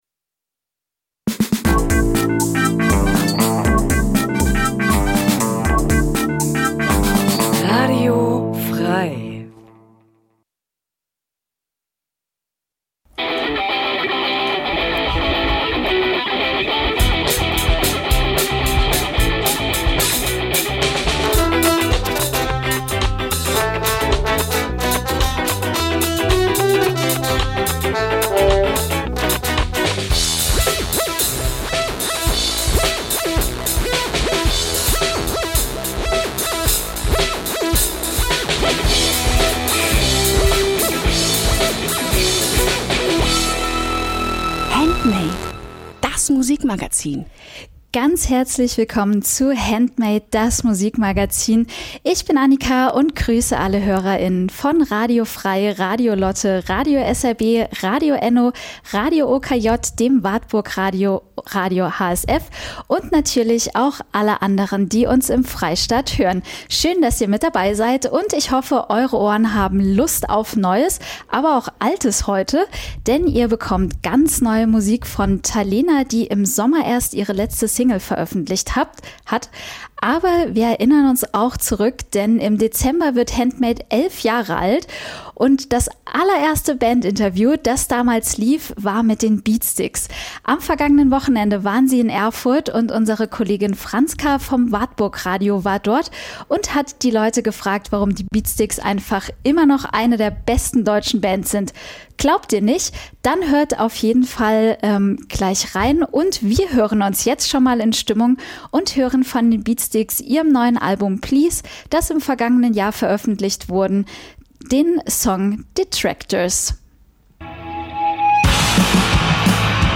Jeden Donnerstag stellen wir euch regionale Musik vor und scheren uns dabei nicht um Genregrenzen. Ob Punk, Rap, Elektro, Liedermacher, oder, oder, oder � � wir supporten die Th�ringer Musikszene. Wir laden Bands live ins Studio von Radio F.R.E.I. ein, treffen sie bei Homesessions oder auf Festivals.